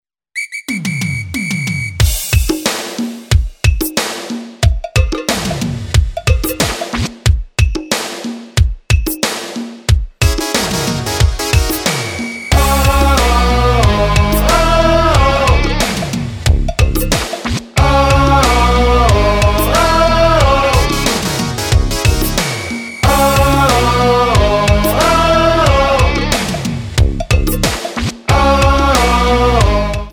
--> MP3 Demo abspielen...
Tonart:C#m Multifile (kein Sofortdownload.
Die besten Playbacks Instrumentals und Karaoke Versionen .